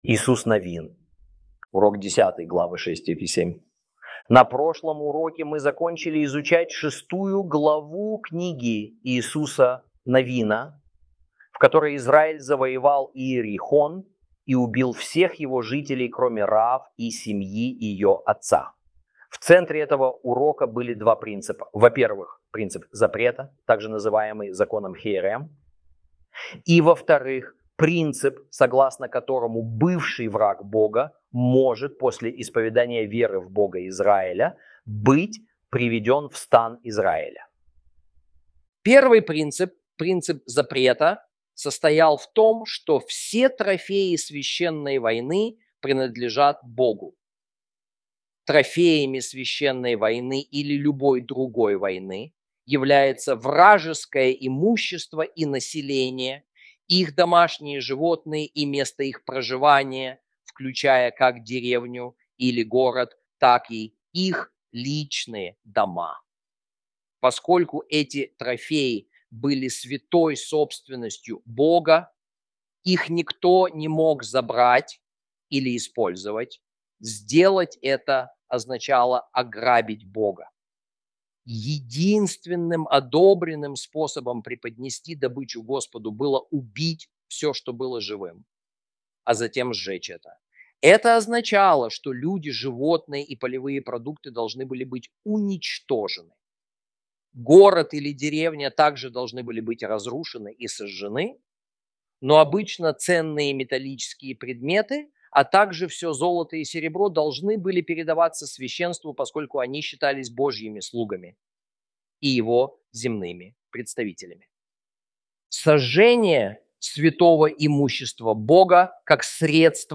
ru-audio-joshua-lesson-10-ch6-ch7.mp3